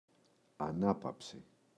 ανάπαψη, η [a’napapsi]